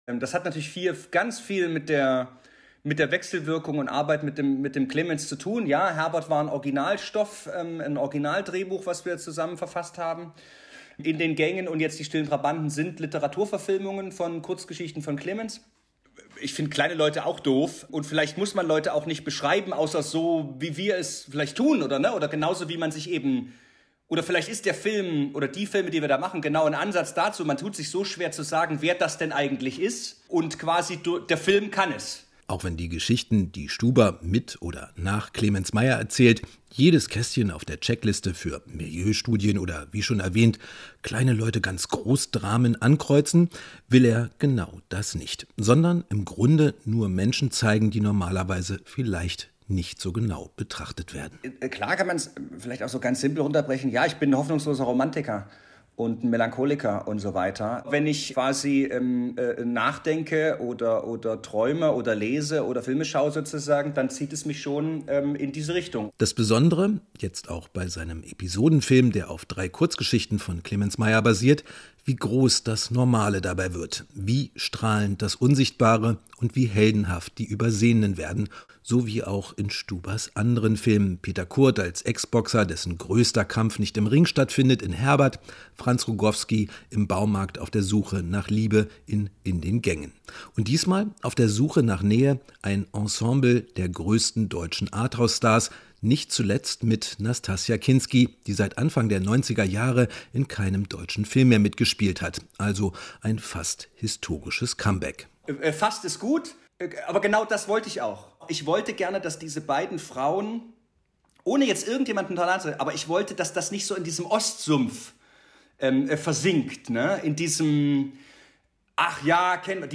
Kinogespräch - Regisseur Thomas Stuber über "Die stillen Trabanten"